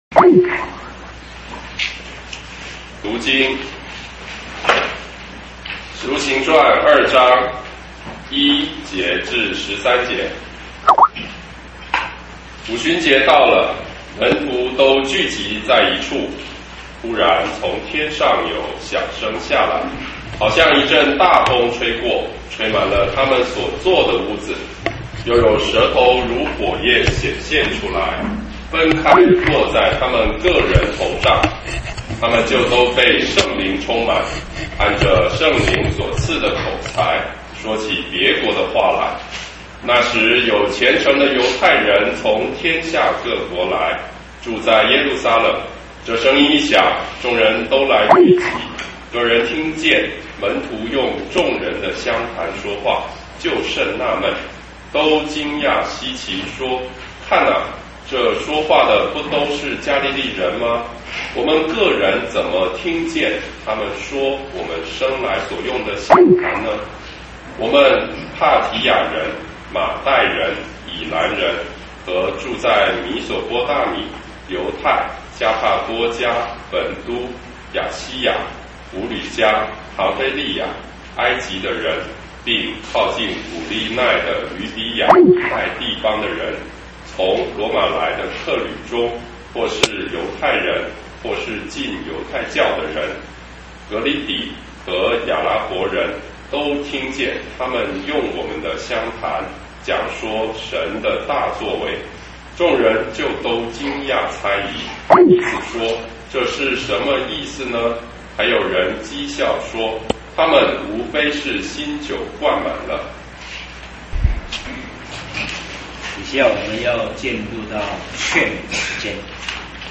地點：嘉義會堂。